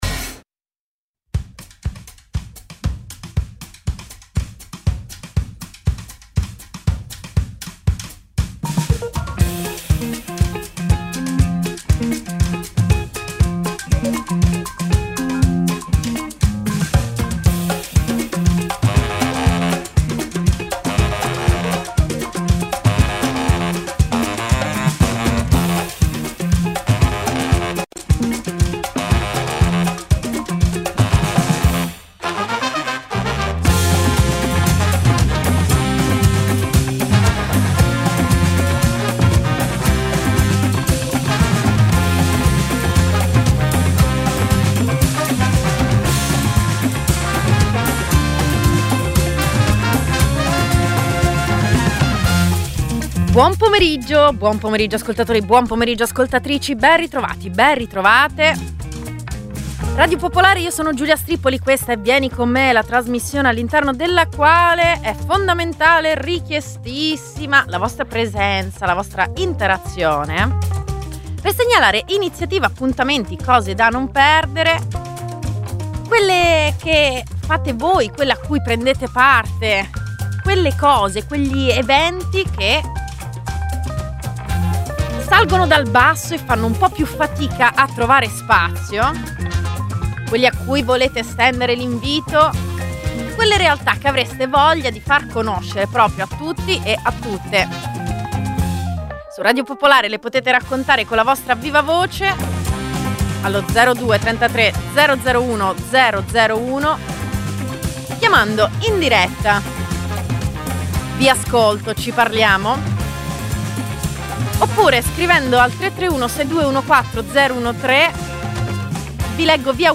Uno spazio radiofonico per incontrarsi nella vita.